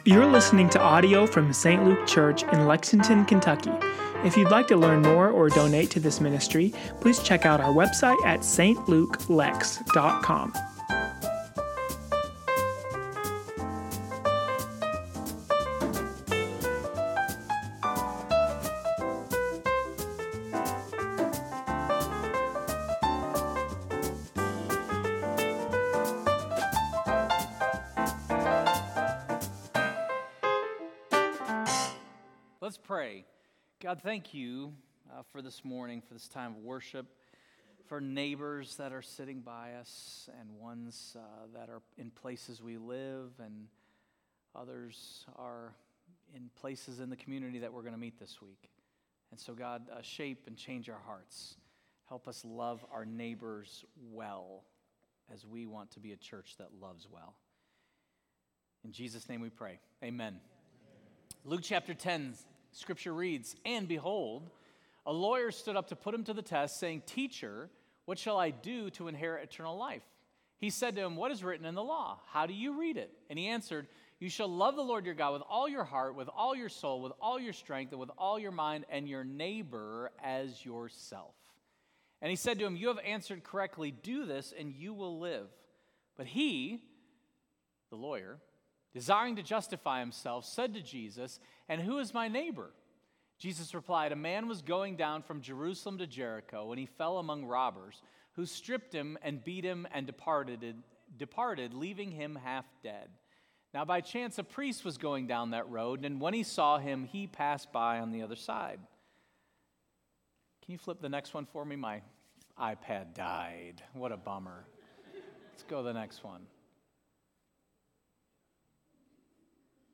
4.19.26-St-Luke-Sermon-Podcast.mp3